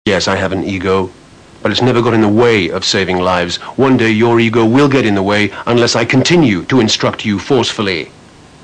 instruct2.wav (59K) - the second "forceful instruction" speech